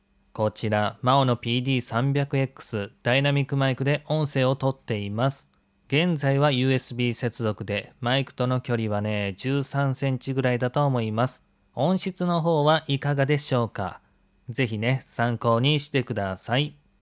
MAONO PD300XT レビュー！USB接続の音声：マイクとの距離13cm
私個人的には、どれも納得できる温かみある音質に驚きを隠せないでいる現状です。
汎用性の高いUSB接続で、これだけの音質レベルを実現しているのだから凄いのひと言！